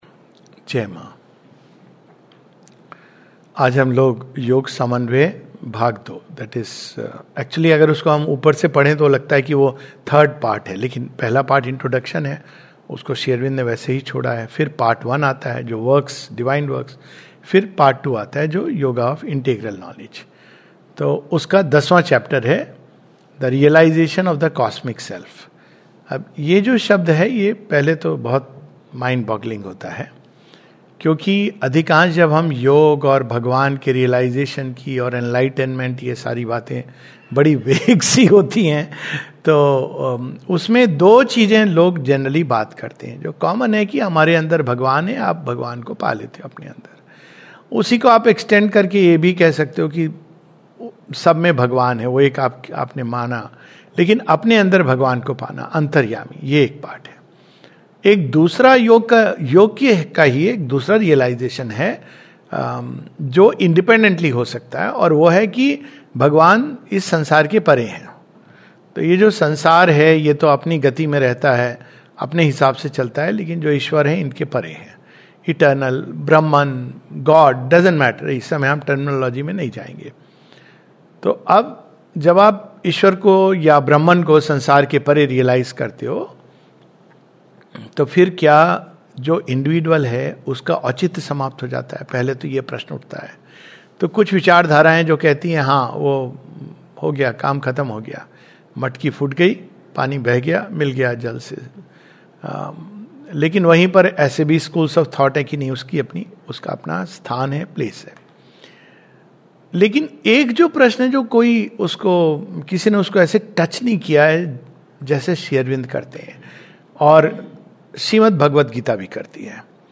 This is a summary of chapter 10 of part 2 of the Synthesis of Yoga. A talk